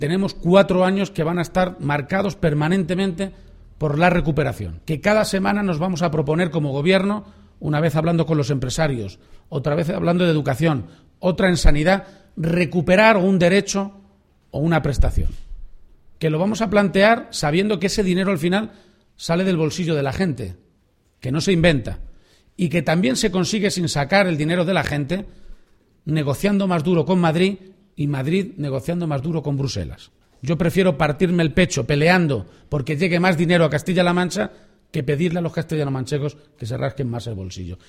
El presidente García-Page ha señalado en la reapertura del CRA de Puente de Vadillos, en Cuenca, que: